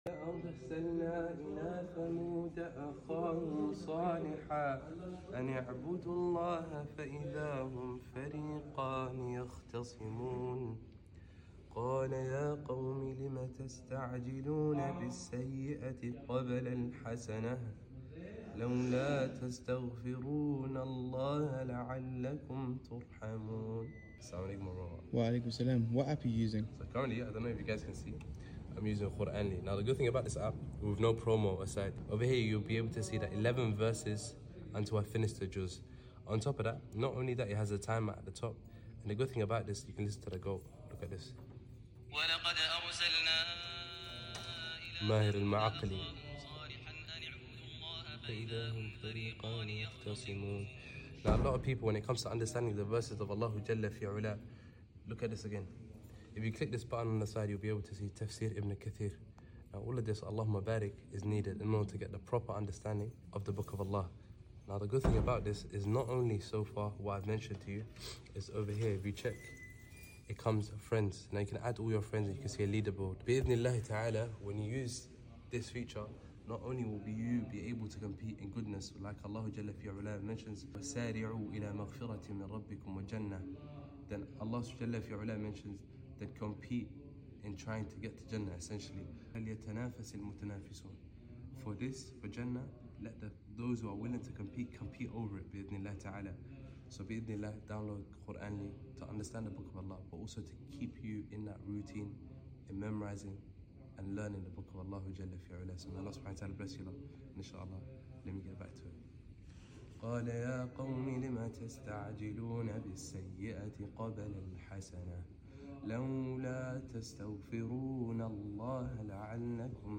I was ill as well so hence the lack of enthusiasm may Allah bless you all Quarnly Is A Platform That Sound Effects Free Download.